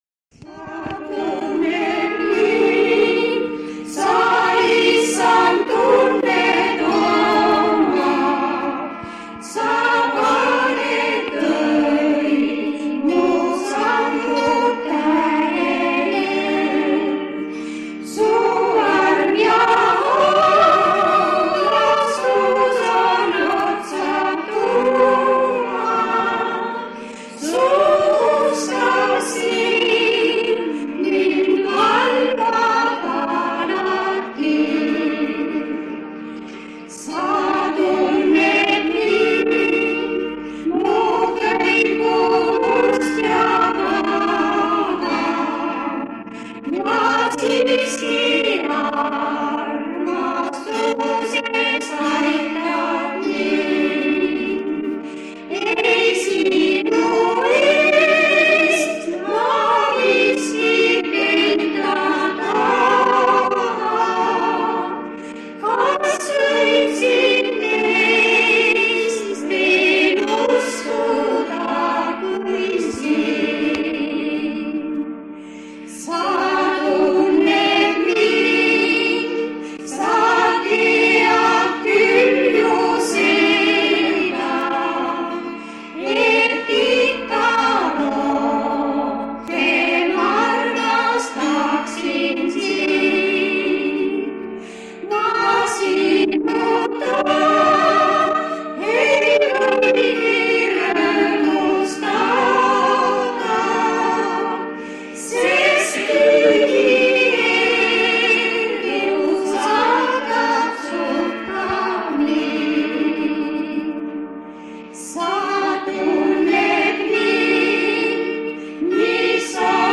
Kõlavad ka laulud kandle saatel.
Koosolekute helisalvestused
Kõnekoosolek vanalt lintmaki lindilt.